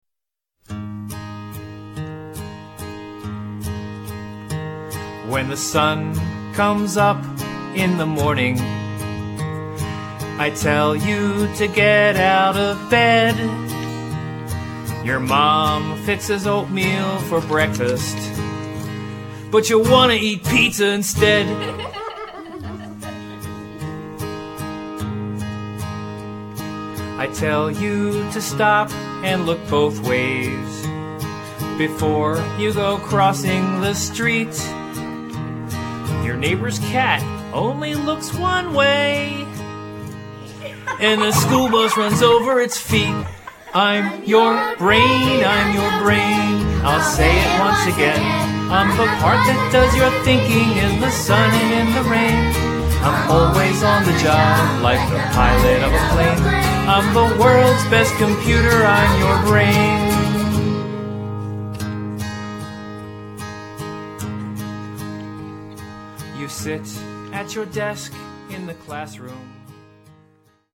--funny kids' music